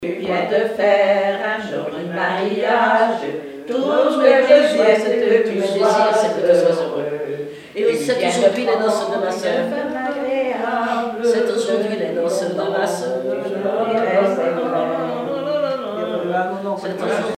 Île-d'Yeu (L')
Genre strophique
Pièce musicale inédite